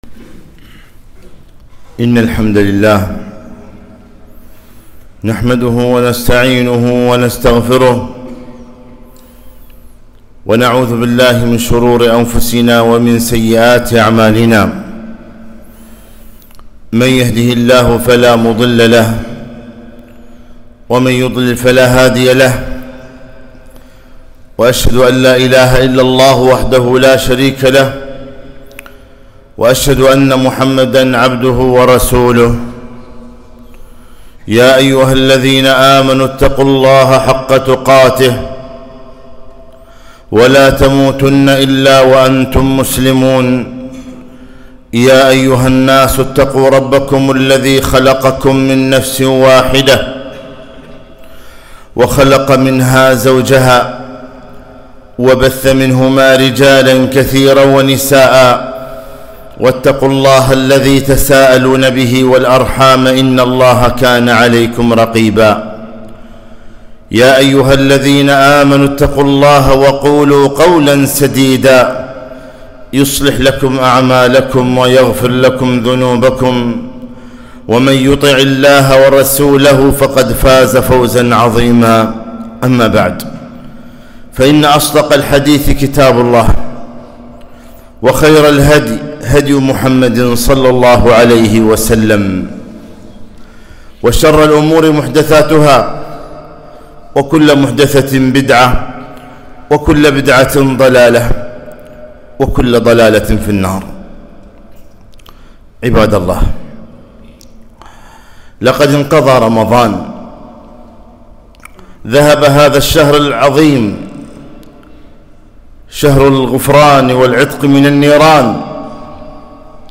خطبة - ( ولا تكونوا كَالَّتي نقضت غزلها من بعد قُوّة)